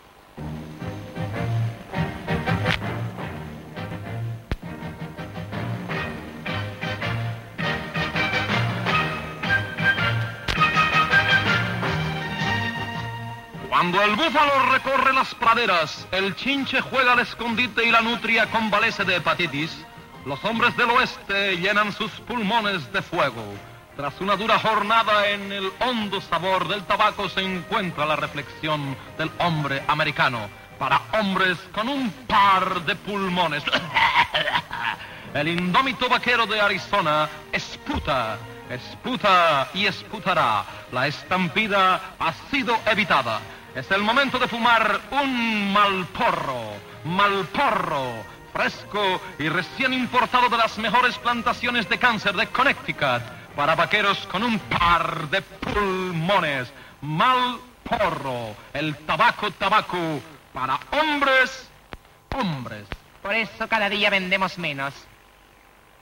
Paròdia de la publicitat del tabac Marlboro